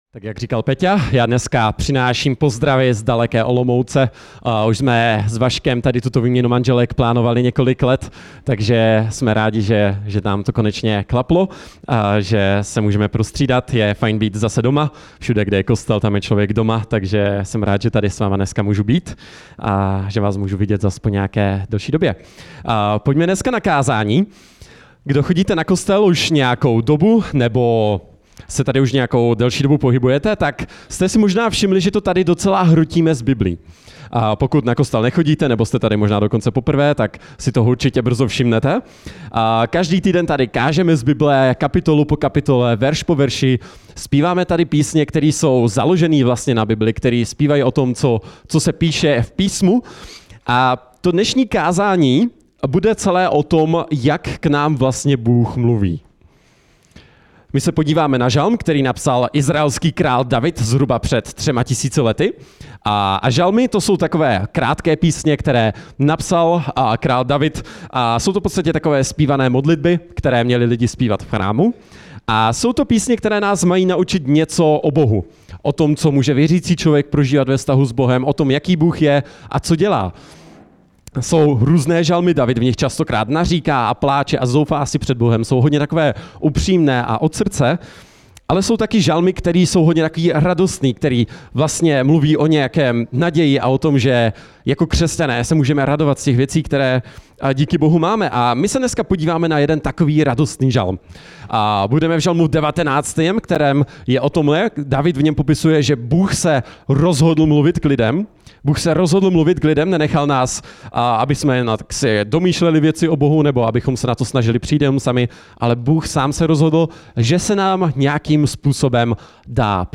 Samostatná kázání